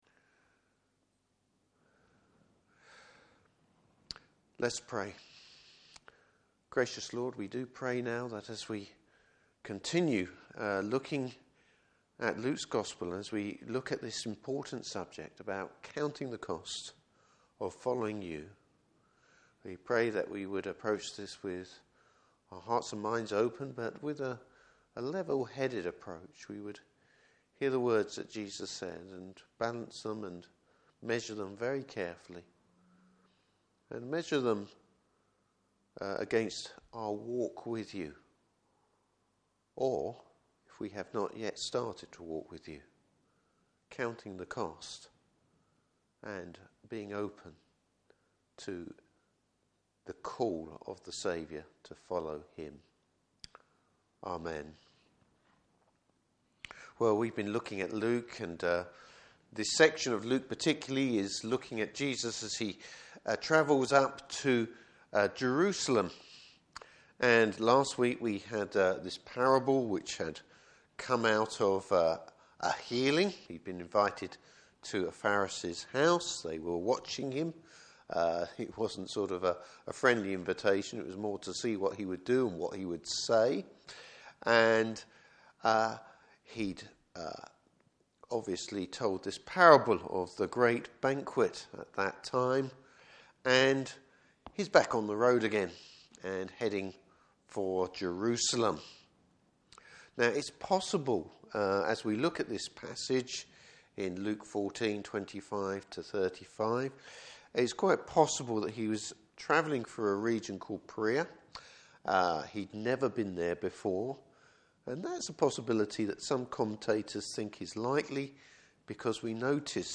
Service Type: Morning Service Bible Text: Luke 14:25-35.